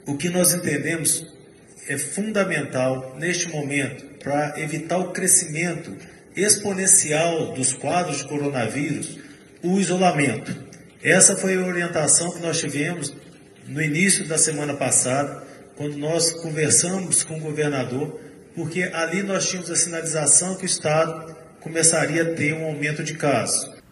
Secretário Estadual de Saúde, Carlos Eduardo Amaral anunciou R$ 51 milhões para Unidades de Pronto Atendimento (UPAs) em Minas Gerais e R$ 32 milhões para investimento primário nos municípios. A coletiva ocorreu concedida nesta quinta-feira, 26, em videoconferência.